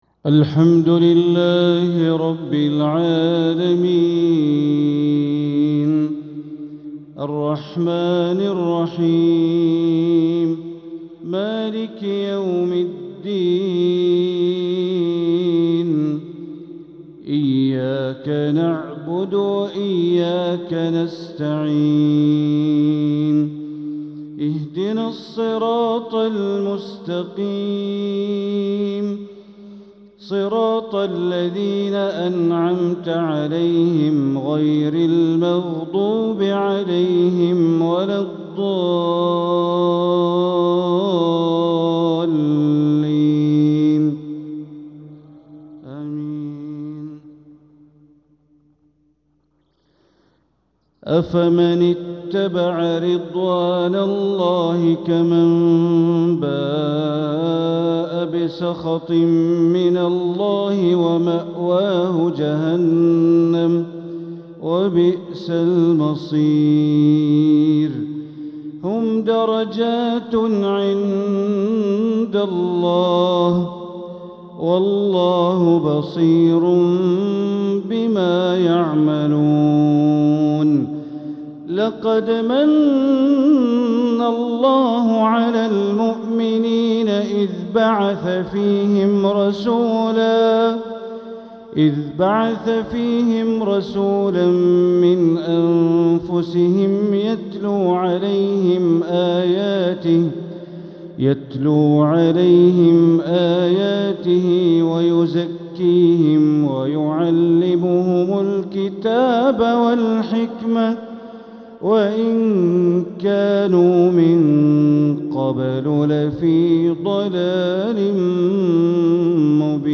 تلاوة من سورتي آل عمران والتوبة مغرب الخميس ٢٩محرم١٤٤٧ > 1447هـ > الفروض - تلاوات بندر بليلة